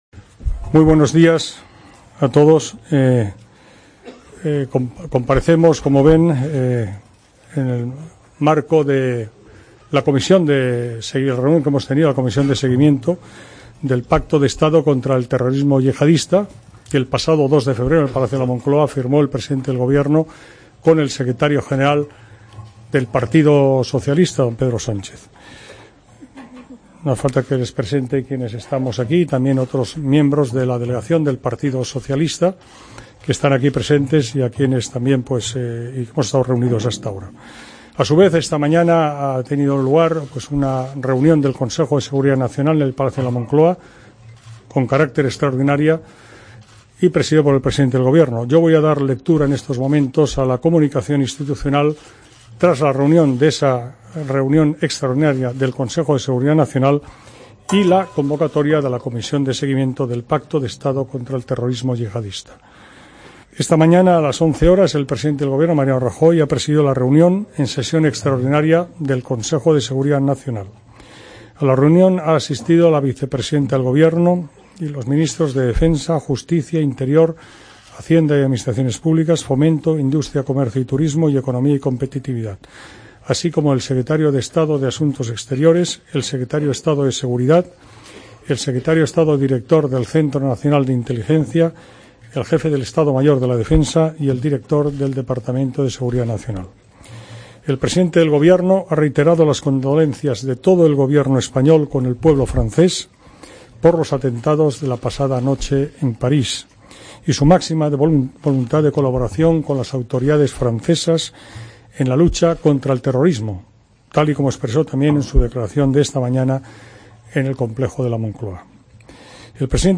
AUDIO: Comparecencia del ministro del Interior, Jorge Fernández Díaz, para hablar del Pacto de Estado sobre terrorismo yihadista